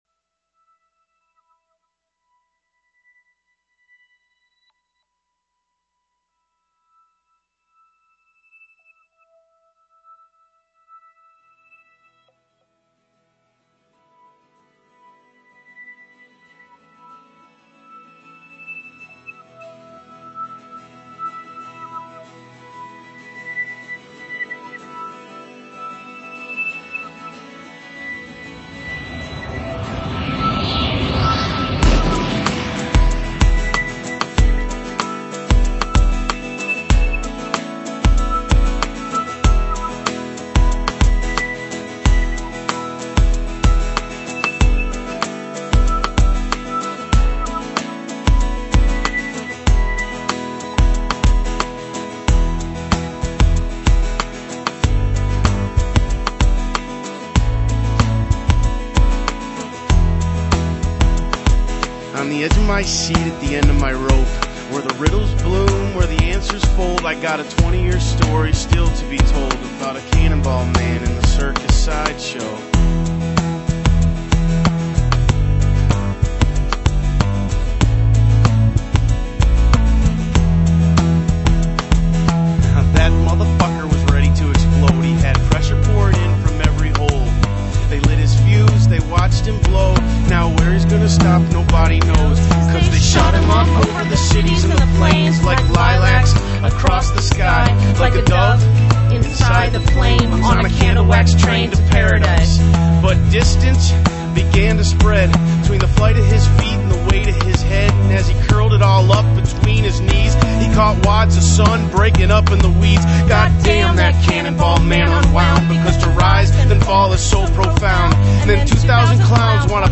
He's equally intense on this album.